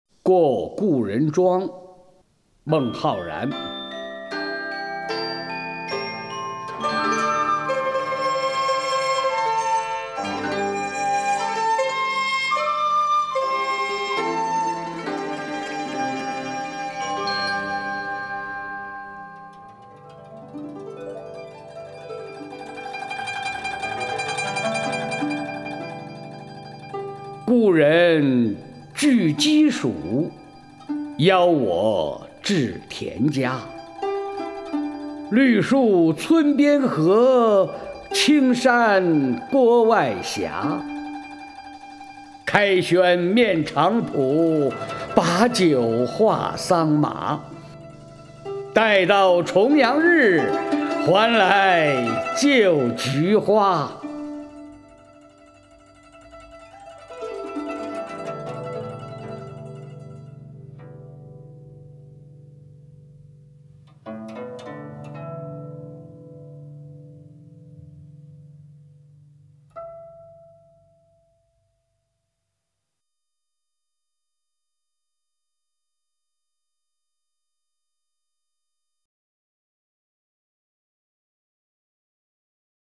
曹灿朗诵：《过故人庄》(（唐）孟浩然) (右击另存下载) 故人具鸡黍，邀我至田家。